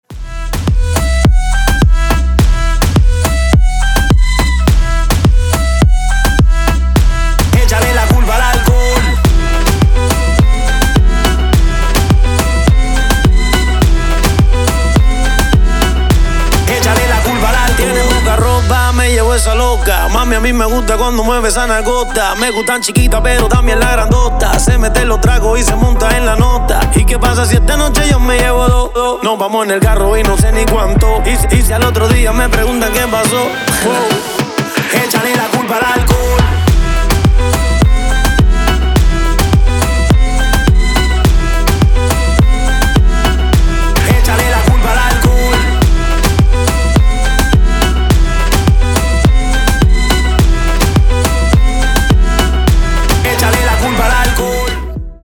мужской вокал
заводные
dance
Electronic
Moombahton
Reggaeton